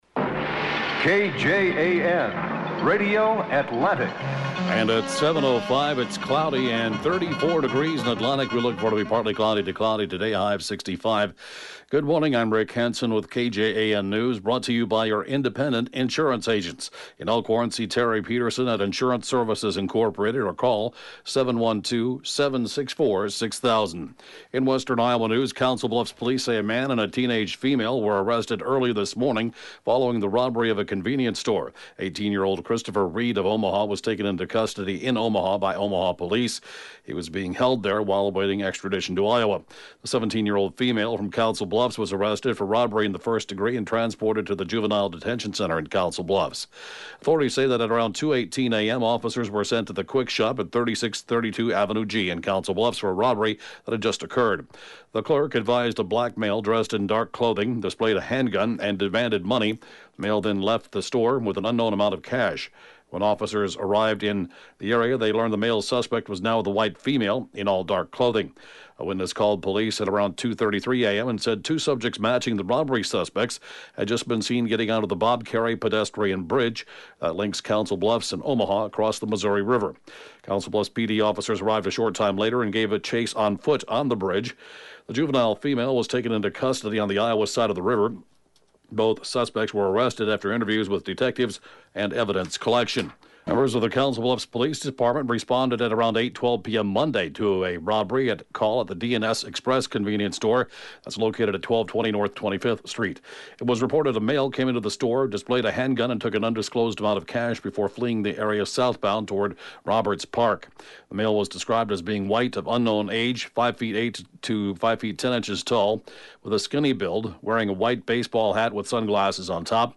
(Podcast) KJAN Morning News & Funeral report, 3/31/20